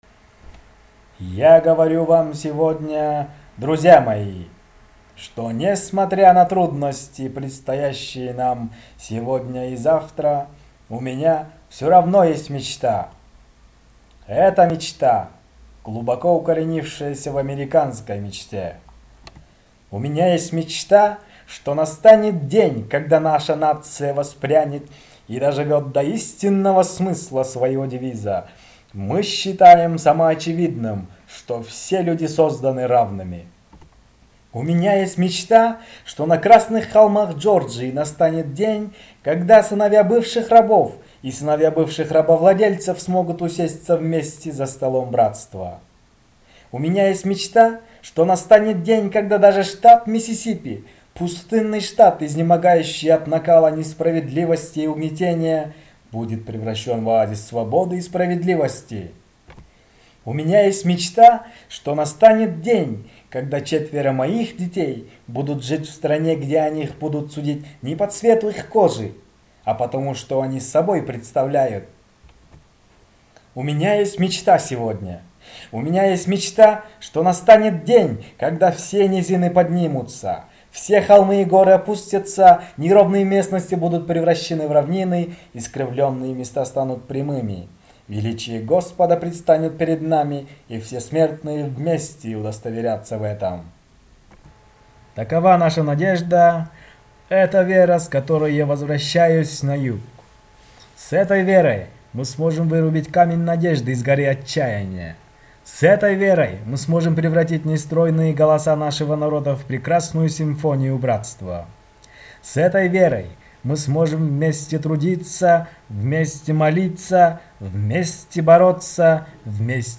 Аудиофайл представлен на конкурс "iDream" в честь 50-летия речи Мартина Лютера Кинга "У меня есть мечта". Для участия в конкурсе необходимо было записать отрывок из речи Мартина Лютера Кинга «У меня есть мечта» на кыргызском, русском или английском языке.